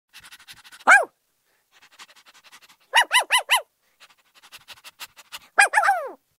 Happy Puppy Barks Effect